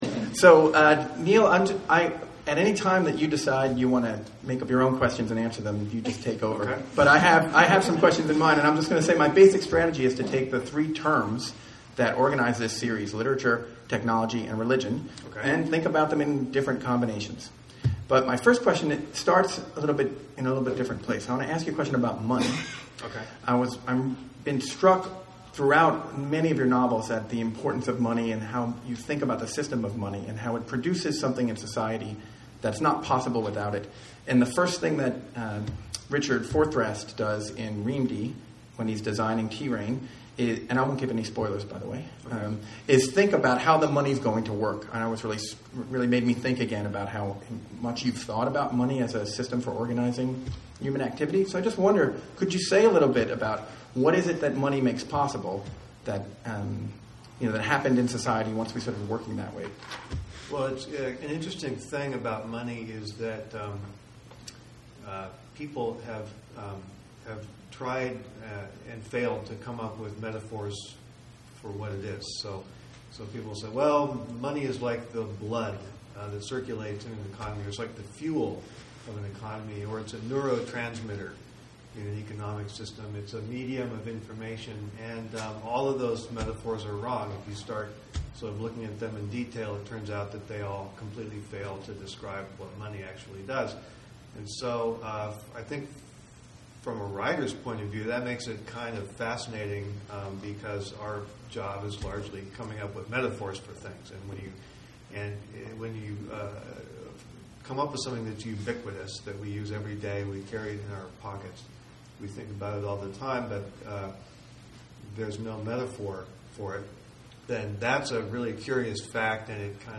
Listen to a conversation with Neal Stephenson, author of Snow Crash, Cryptonomicon, Anathem, and most recently Reamde.